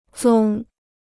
宗 (zōng): school; sect.